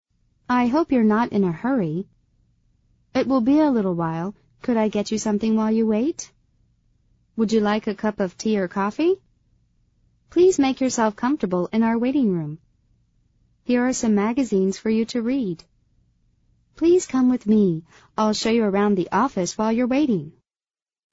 美语会话实录第131期(MP3+文本):Make yourself comfortable